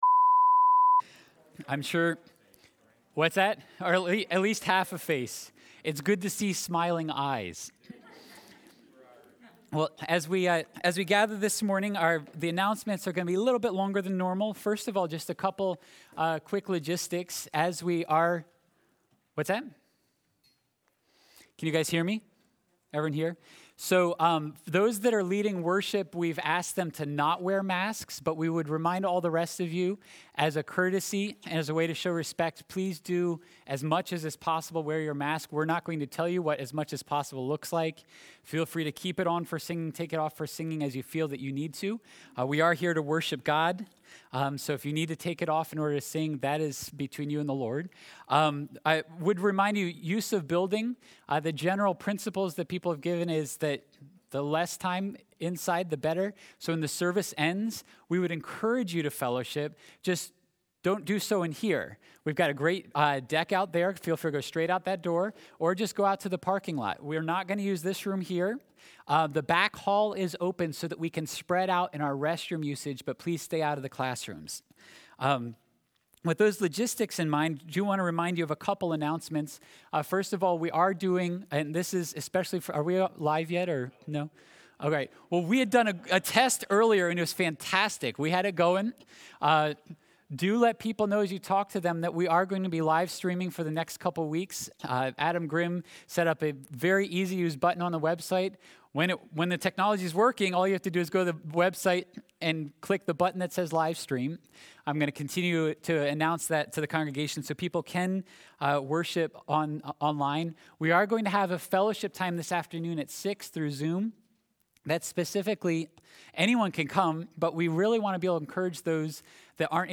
Sunday Worship, May 31. Sermon: Desiring God in Community